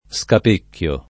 vai all'elenco alfabetico delle voci ingrandisci il carattere 100% rimpicciolisci il carattere stampa invia tramite posta elettronica codividi su Facebook scapecchiare v. (tecn.); scapecchio [ S kap % kk L o ], ‑chi